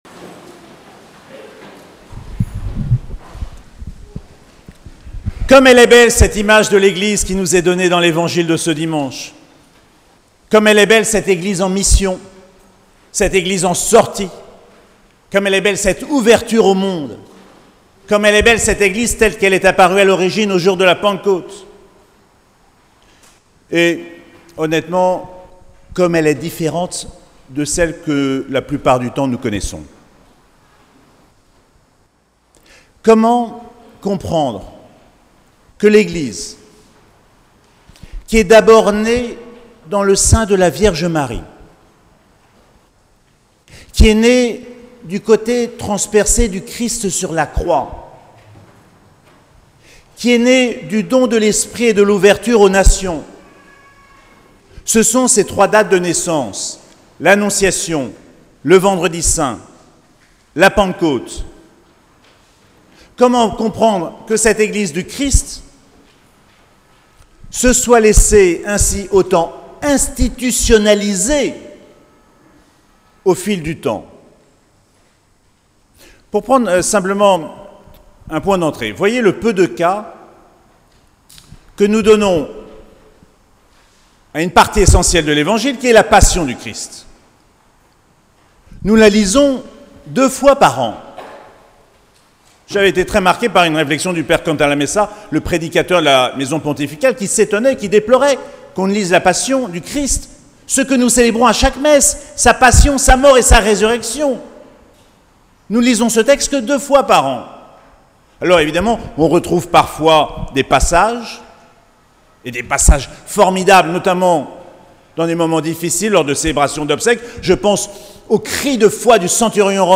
14ème dimanche du Temps Ordinaire - 3 juillet 2022
Précédentes homélies